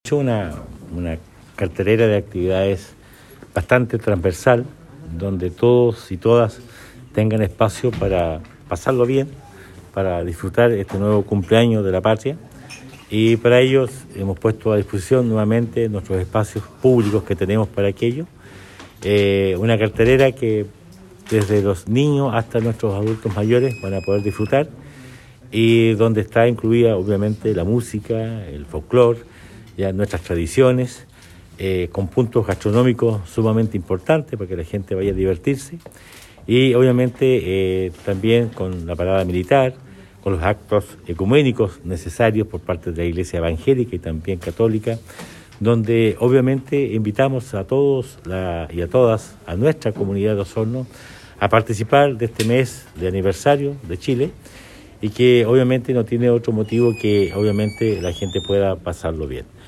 Durante el lanzamiento de esta programación, el alcalde Emeterio Carrillo destacó que este año tendremos un fin de semana largo de celebraciones por el aniversario número 213 de nuestra patria, que se extenderán desde el viernes 15 hasta el martes 19 de septiembre.